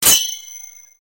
SFX字幕砰的声音音效下载
SFX音效